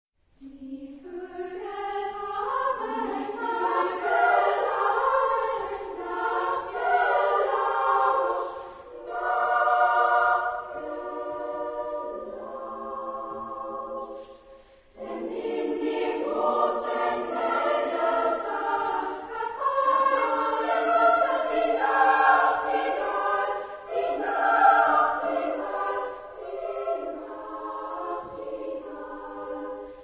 Genre-Stil-Form: Zyklus ; Chorlied ; weltlich
Chorgattung: SSAA  (4 Kinderchor Stimmen )
Tonart(en): frei
Aufnahme Bestellnummer: 5.Deutscher Chorwettbewerb, 1998